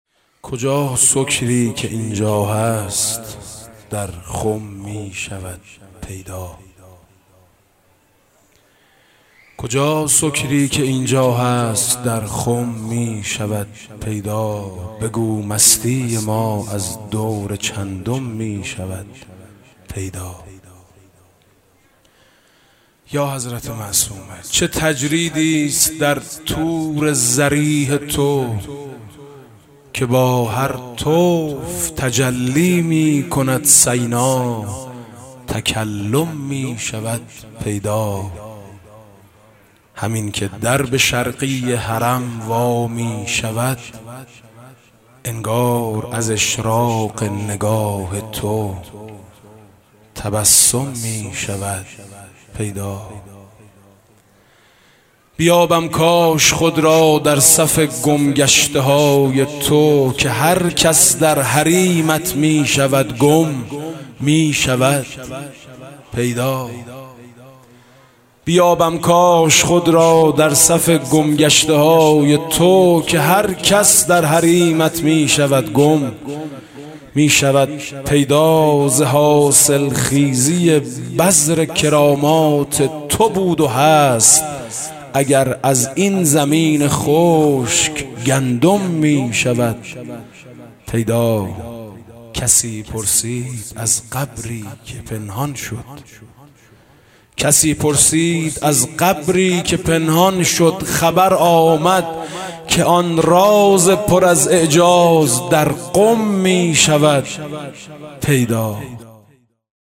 مدح: آن راز پر از اعجاز در قم می شود پیدا